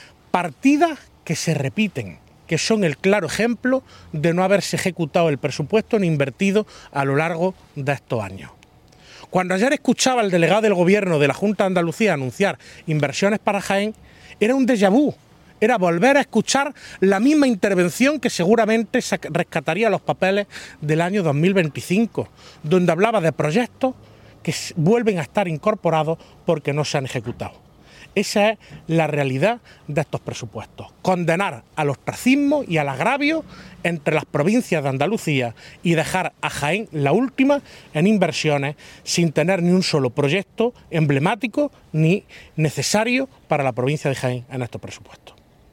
Cortes de sonido